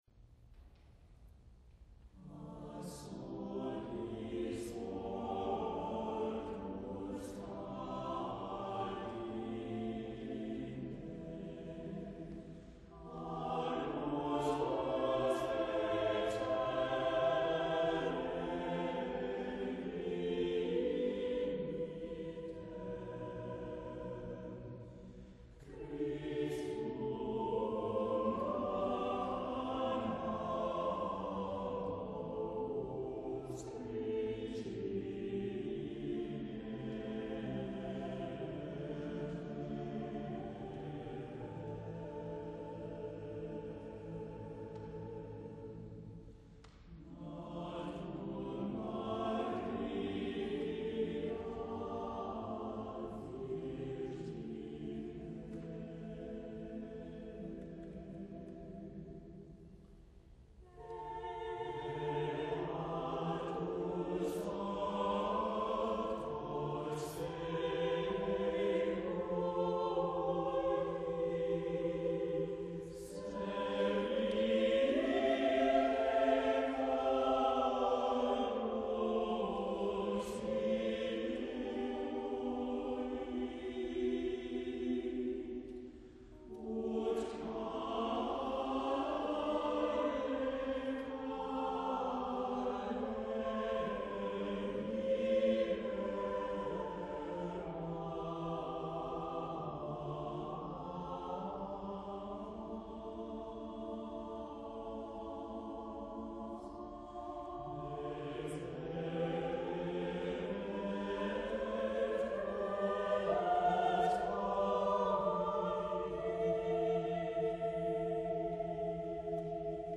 • Christmas carol of intensity, yet with a gentle lilt
• occasional six-part writing adds brilliance and depth
• slower pace demands good breath control and stamina